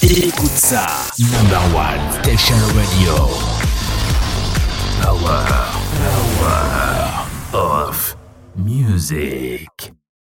Radiobildgebung
Micro SE Electronics und Beats Mixr